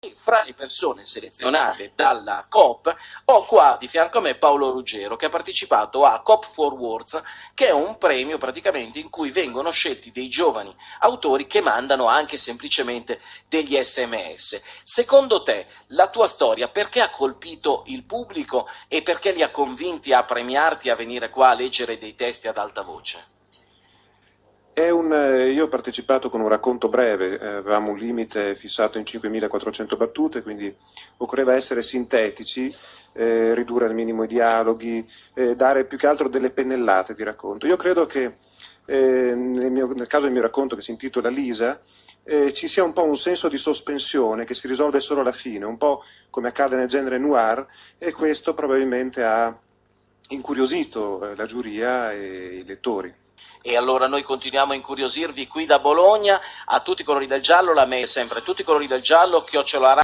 commento.mp3